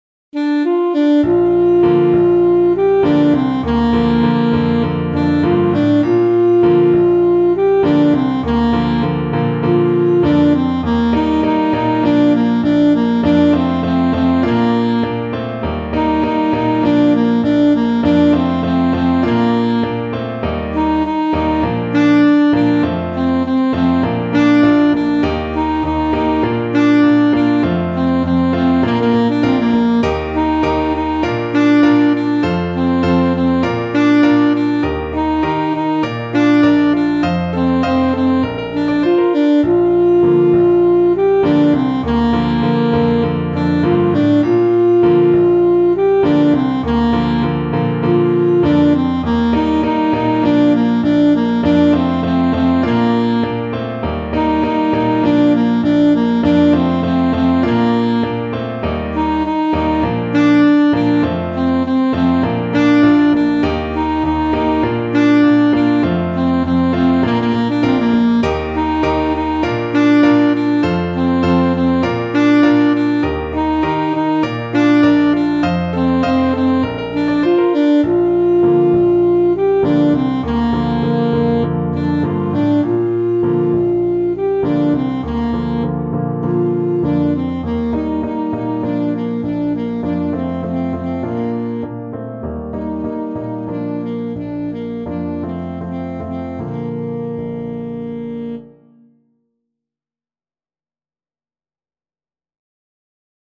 Alto Saxophone
Joyfully =c.100
4/4 (View more 4/4 Music)
Bb4-G5
Traditional (View more Traditional Saxophone Music)